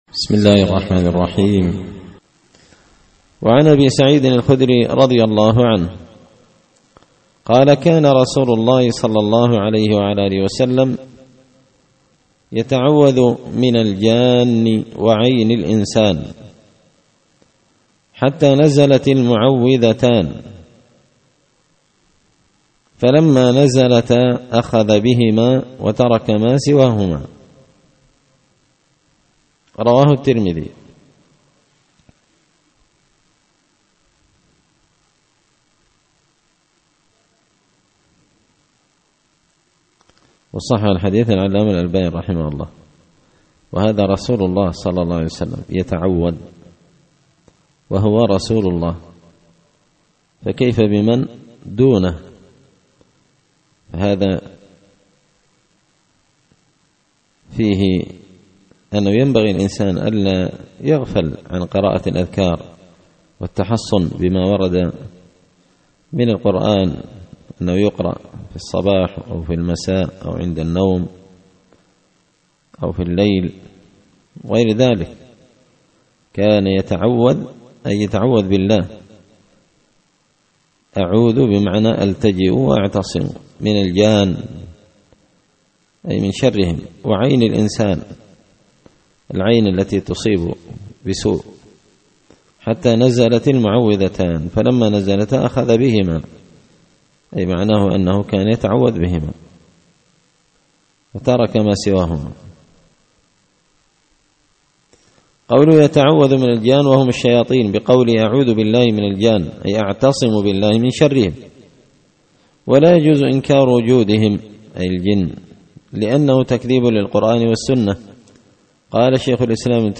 الأحاديث الحسان فيما صح من فضائل سور القرآن ـ الدرس الستون
دار الحديث بمسجد الفرقان ـ قشن ـ المهرة ـ اليمن